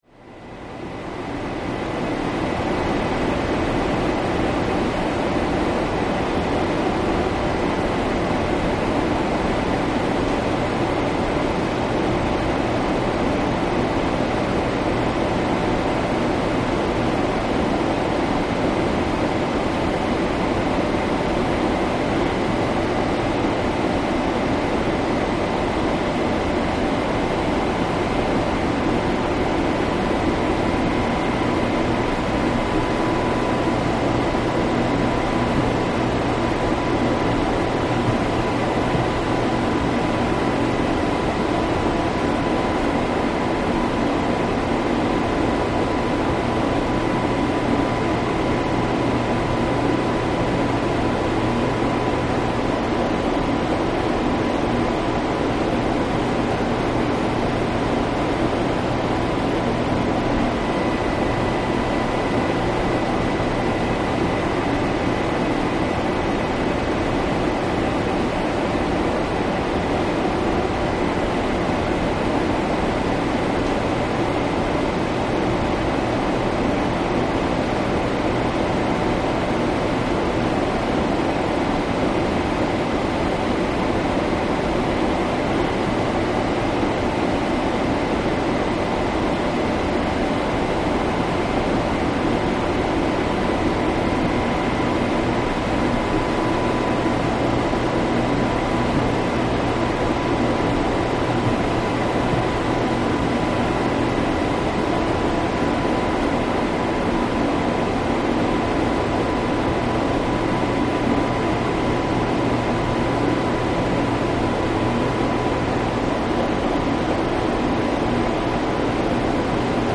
Industrial Fans Blowers Steady